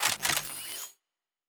Weapon 12 Reload 2 (Laser).wav